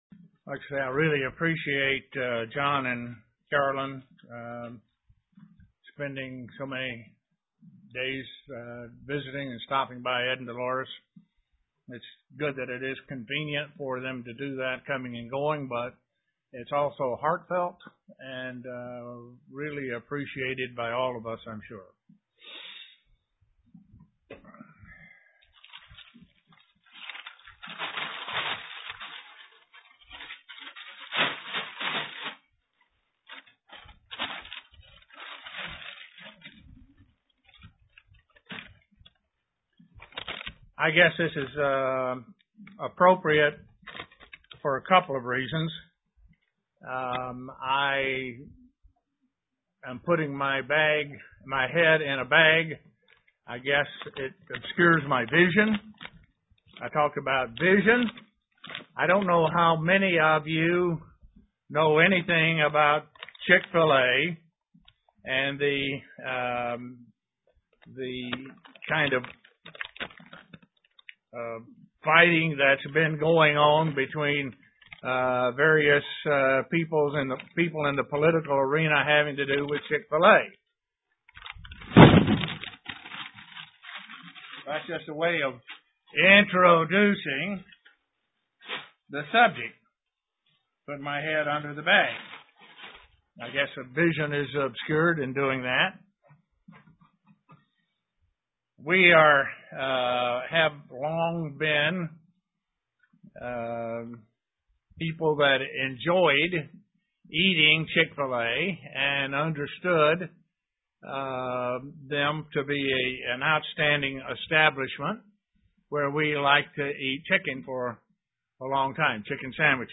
Given in Elmira, NY
Print Set of principles and how we should behave UCG Sermon Studying the bible?